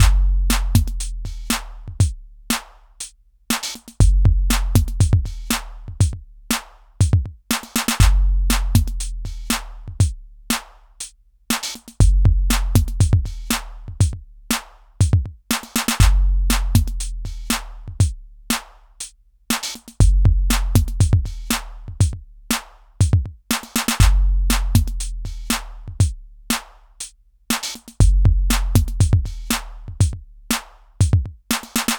Bucle de percusión electrónica
Música electrónica
repetitivo
rítmico
sintetizador